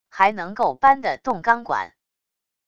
还能够搬得动钢管wav音频生成系统WAV Audio Player